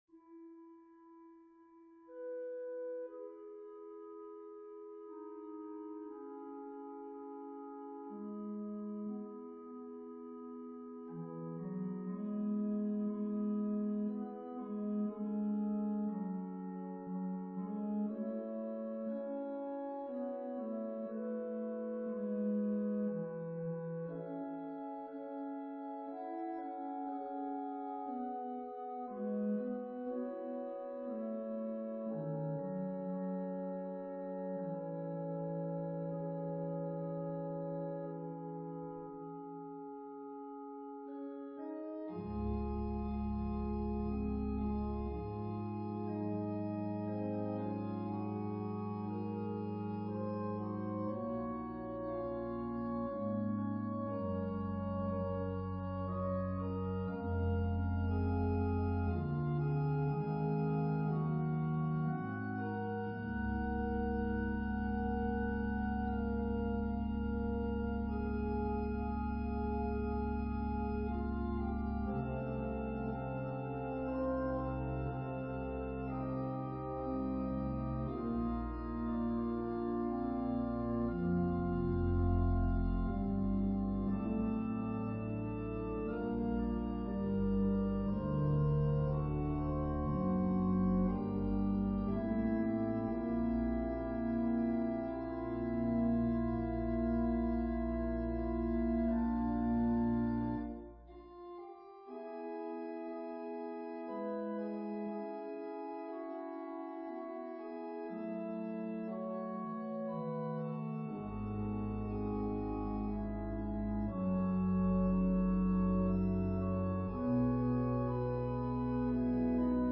An organ solo arrangement